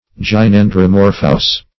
Gynandromorphous \Gy*nan`dro*mor"phous\
(j[i^]*n[a^]n`dr[-o]*m[^o]r"f[u^]s), a. (Zool.)